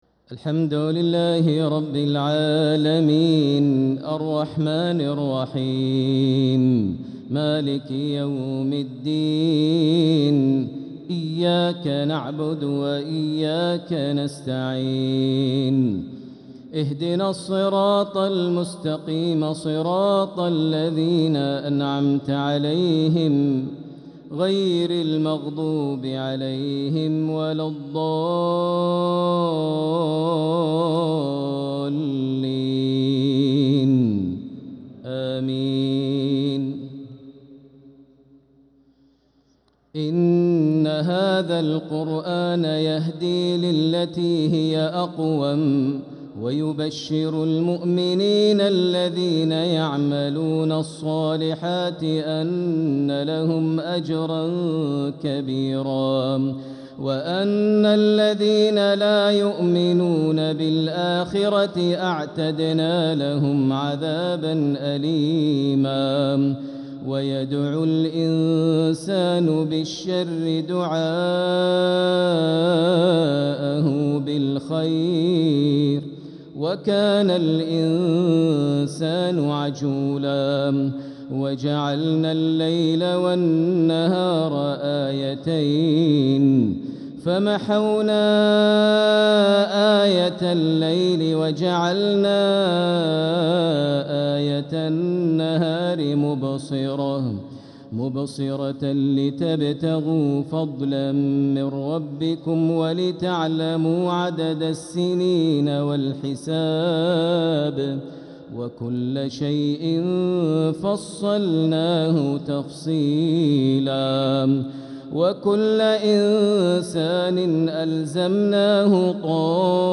عشاء الأحد 9-9-1446هـ من سورة الإسراء 9-17 | Isha prayer from Surat Al-Isra 9-3-2025 > 1446 🕋 > الفروض - تلاوات الحرمين